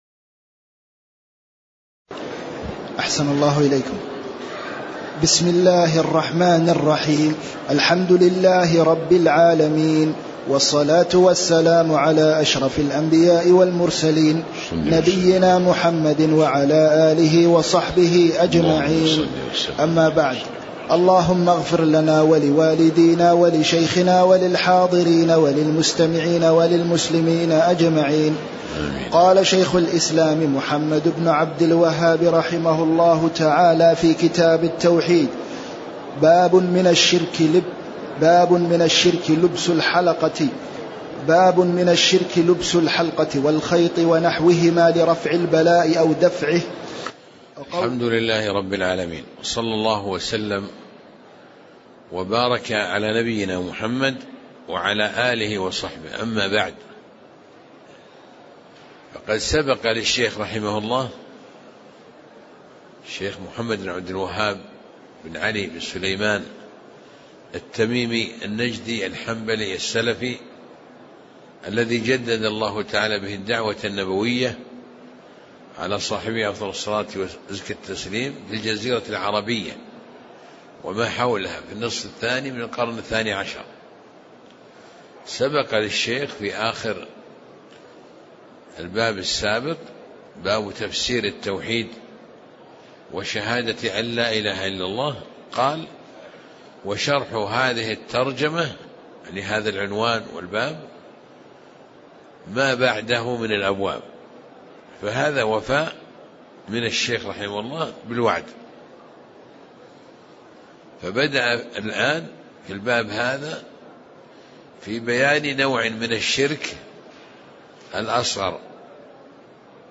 تاريخ النشر ٧ رجب ١٤٣٨ هـ المكان: المسجد النبوي الشيخ